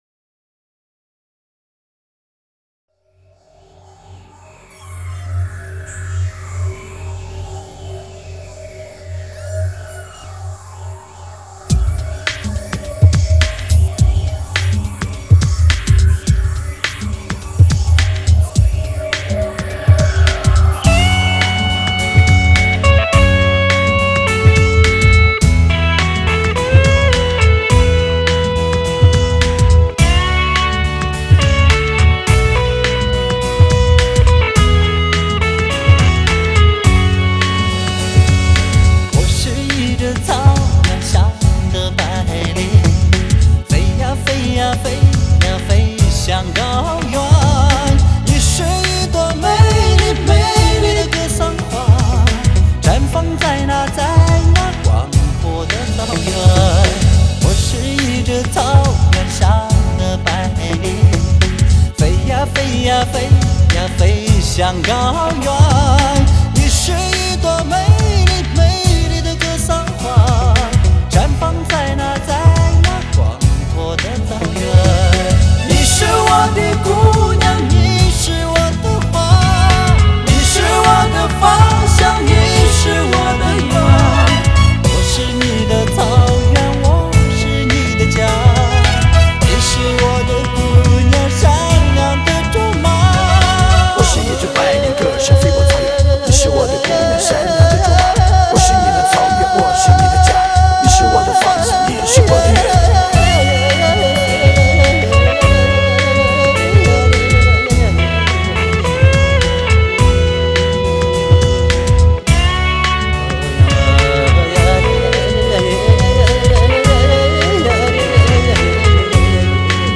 后期母带德国精制
黑胶高保真和低噪音于一体品味视听最高境界